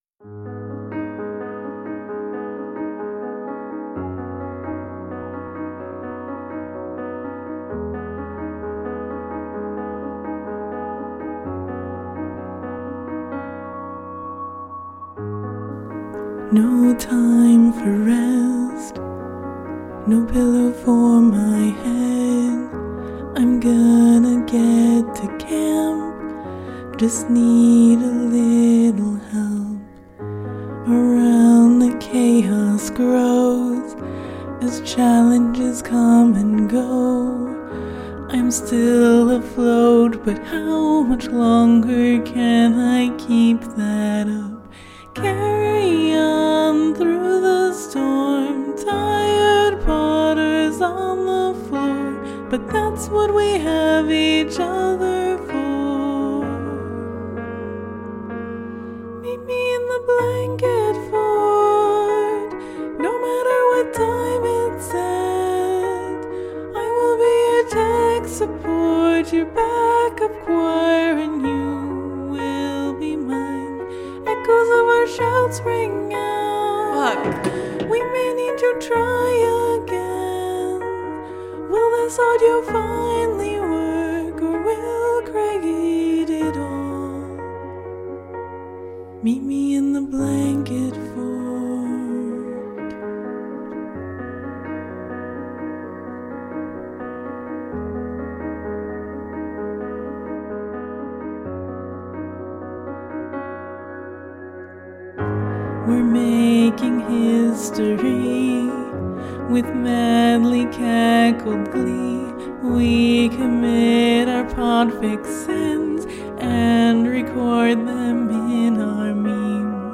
collaboration|ensemble